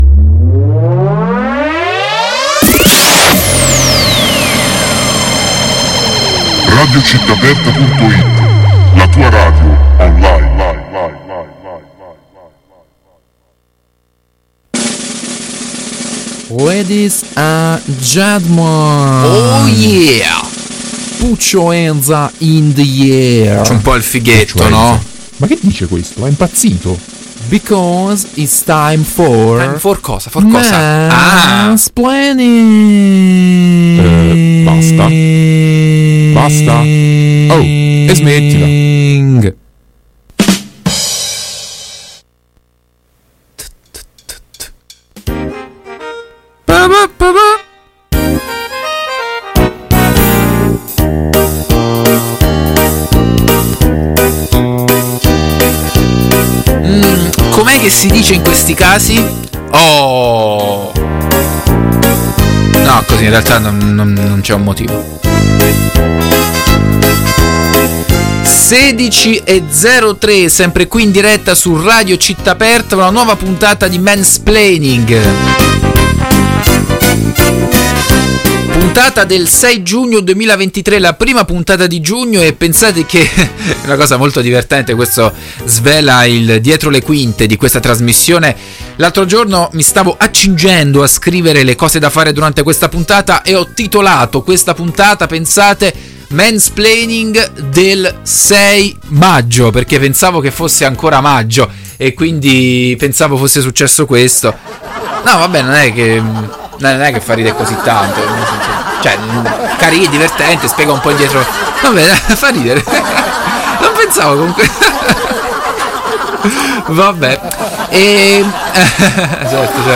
In questi giorni sono giunte numerose segnalazioni alla redazione di “Mansplaining” in merito a episodi di violenza a Roma. Grazie a tutto lo staff (e alle forze dell’ordine) siamo riusciti ad intervistare un soggetto molto problematico, che ha cercato di giustificare gli episodi di cui si è reso protagonista.